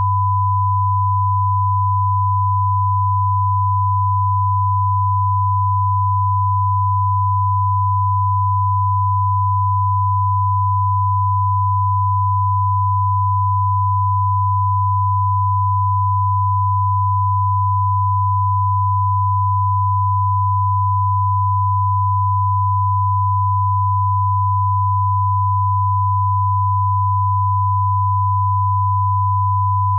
These test files contain -10dBFS 1kHz tone on the left channel and 100Hz tone on the right channel.
48k_2ch_24_tones.wav